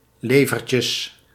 Ääntäminen
Ääntäminen US Tuntematon aksentti: IPA : /lɪvə(r)/ Haettu sana löytyi näillä lähdekielillä: englanti Käännös Ääninäyte Substantiivit 1. lever {m} 2. levertjes Liver on sanan live komparatiivi.